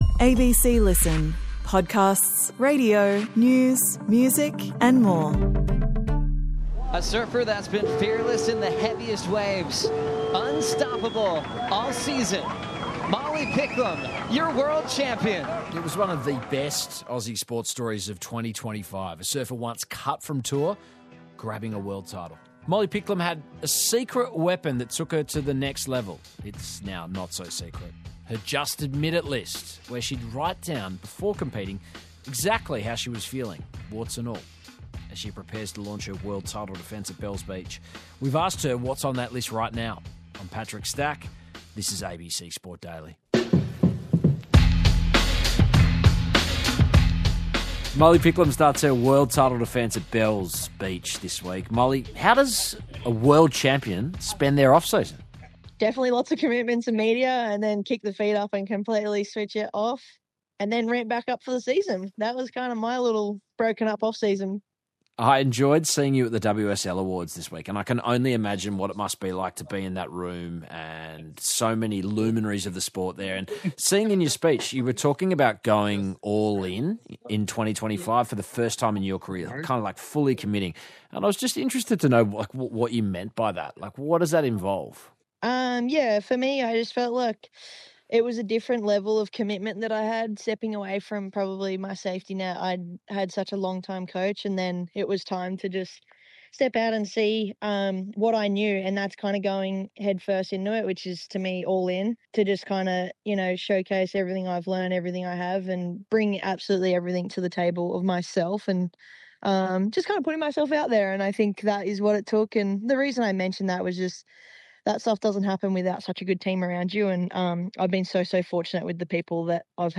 Featured: Molly Picklum, surfing world champion.